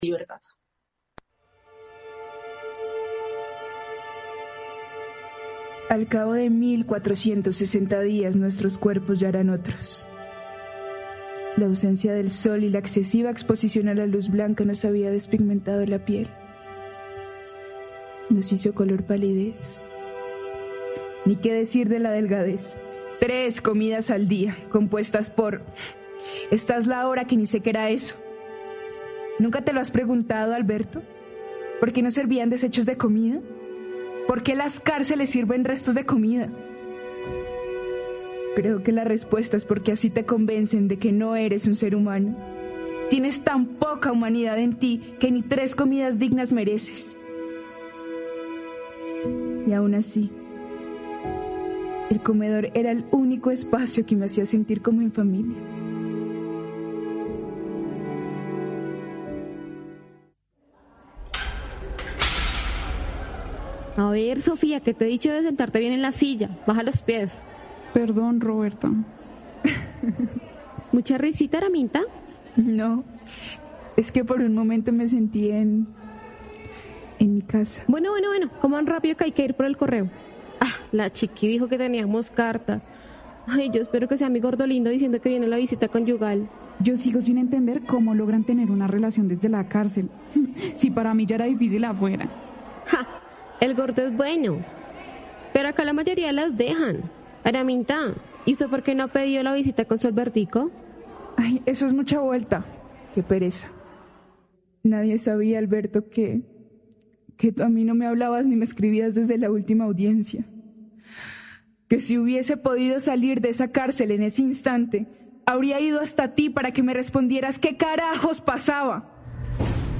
Este 5º capítulo muestra como Juan Diego y Felipe deberán investigar qué sucede con su amigo Alberto y las reuniones que mantienen con guardias de la cárcel, mientras Sofía y Araminta deberán hacer posible por intentar develar que hay detrás de su montaje judicial. Este quinto capítulo corresponde a la radio novela «partículas de Libertad» elaborada por el colectivo Expreso Libertad con la colaboración de Contagio Radio Digital , un proyecto de la Fundación Mundubat y el apoyo del Ayuntamiento de Vitoria-Gasteiz.